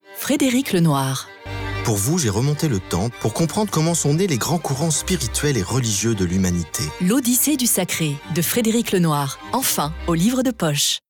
Spot radio
Spot radio L'Odyssée du sacré (Essai philosophique) Spot radio Frédéric Lenoir voix bienveillante voix souriante Spot radio Catégories / Types de Voix Extrait : Télécharger MP3 Spot radio Catégories / Types de Voix